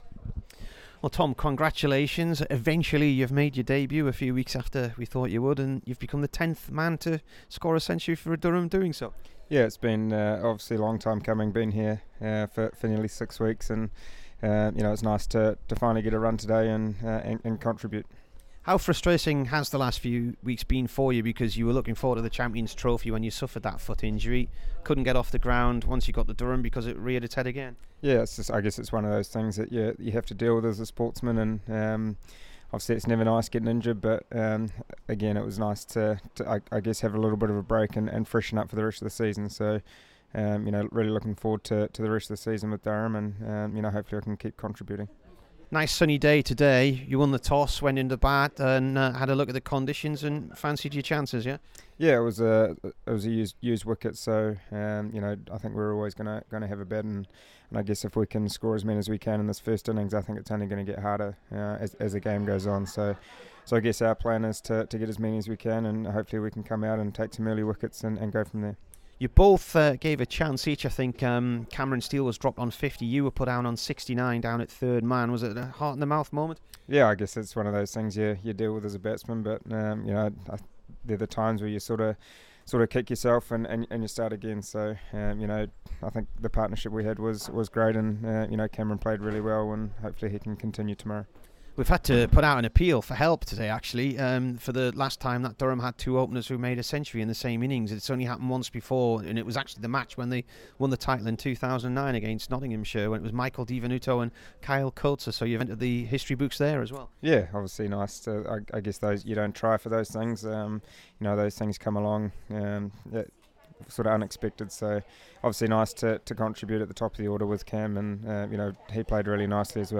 Tom Latham int